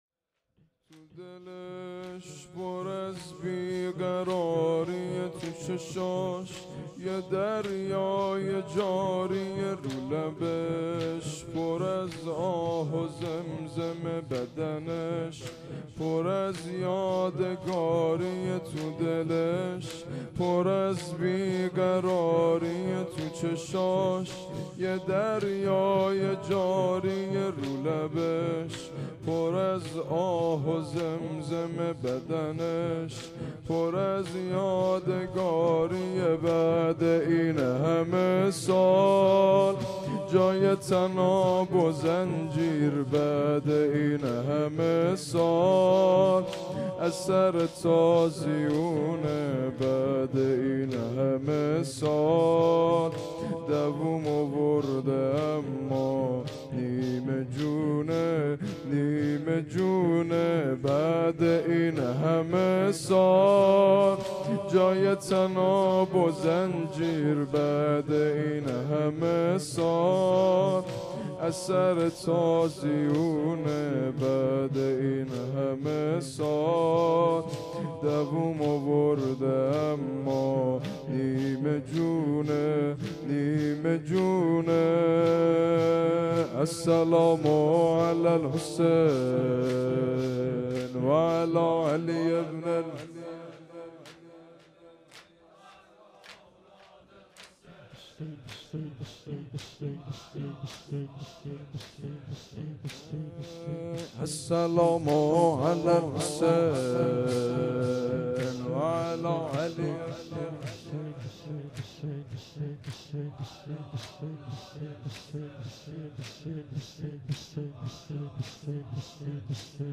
مجلس روضه هفتگی و شهادت امام سجاد علیه السلام ۲۰ مرداد ۱۴۰۱